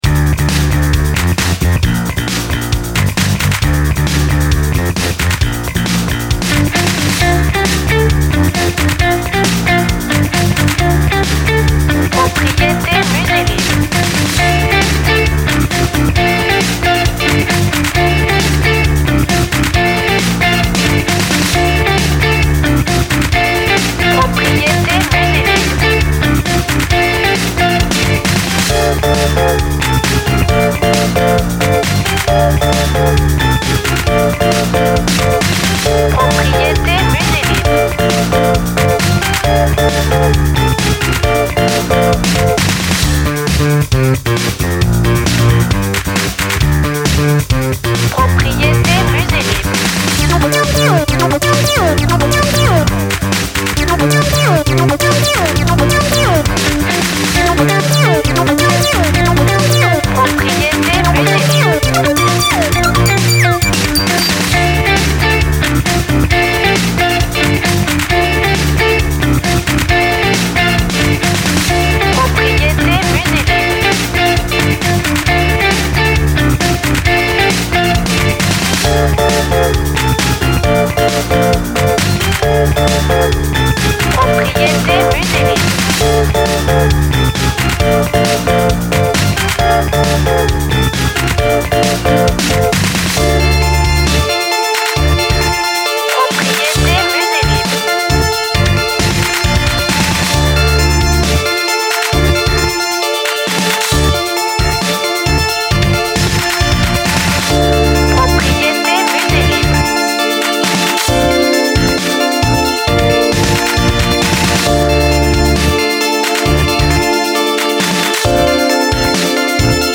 Chanson pop tendance et pleine d’énergie.
BPM Très rapide